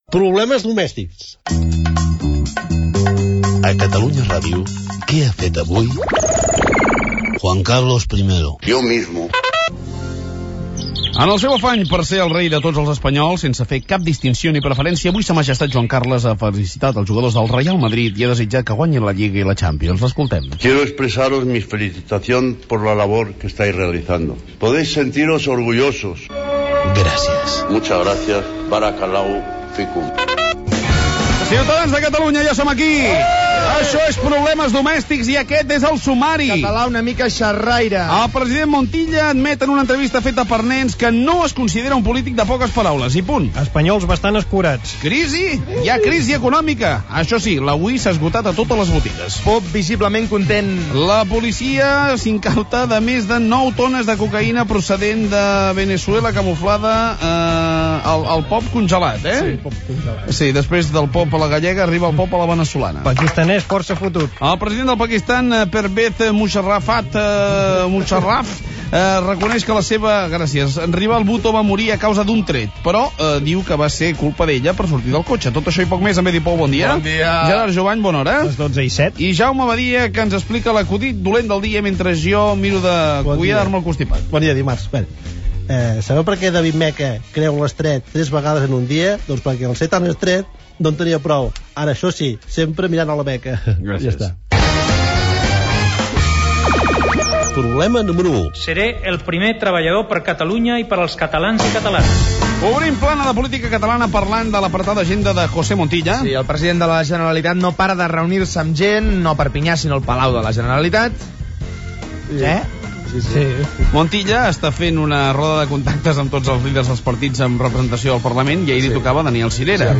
Entreteniment
“Problemes domèstics”, presentat per Manel Fuentes, va començar a Catalunya Ràdio el mes de setembre de 1999.